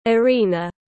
Arena /əˈriː.nə/
Arena.mp3